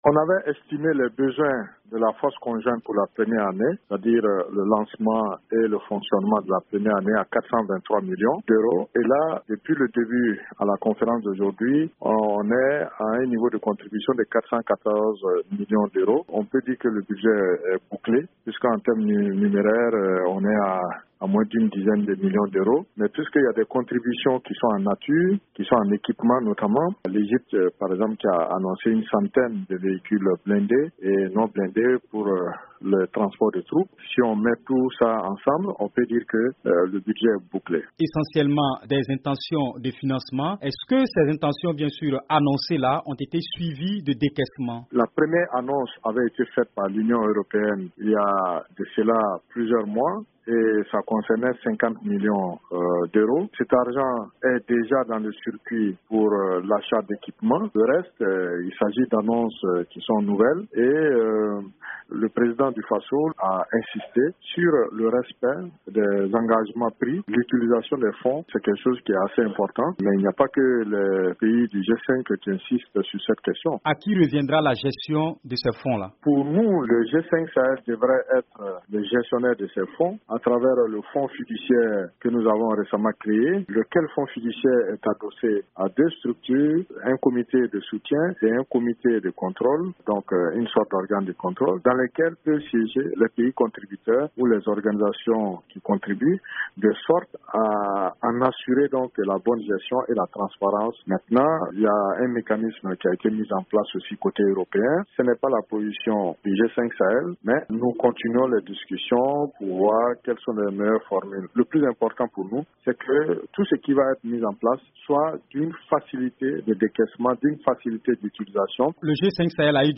Alpha Barry, ministre burkinabè des affaires étrangères
Brèves Sonores